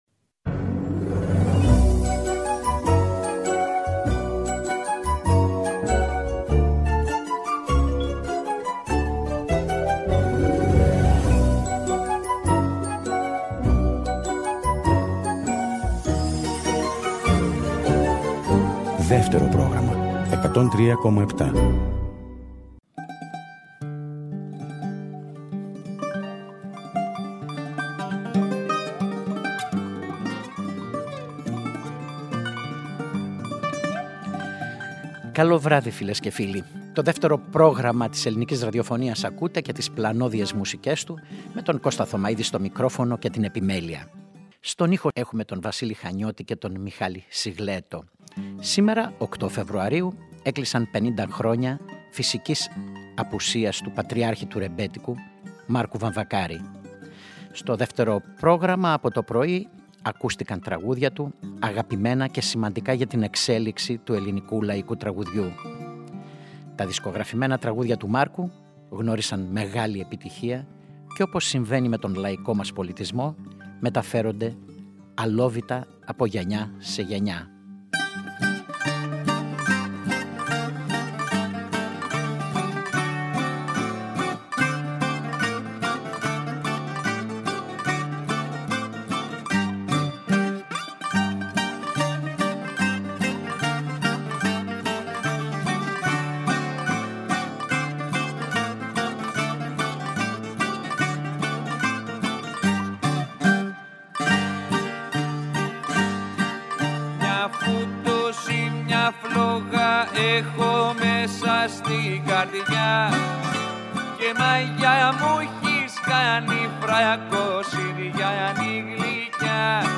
φωνή, μπαγλαμά
φωνή, κιθάρα
φωνή, μπουζούκι
Ανάμεσα στα τραγούδια συνομιλούν για τον Μάρκο Βαμβακάρη, τη ζωή του, τα τραγούδια, την δισκογραφία, την μουσική του κληρονομιά. Η ηχογράφηση έγινε στο στούντιο C της Ελληνικής Ραδιοφωνίας